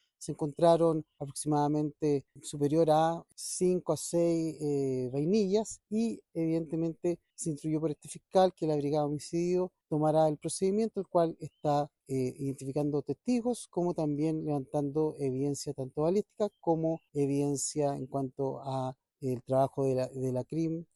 El fiscal del Equipo contra el Crimen Organizado y Homicidios (ECOH), Rodrigo Moya, indicó que se están “identificando testigos” y “levantando evidencia balística” en el sitio del suceso para dar con los criminales.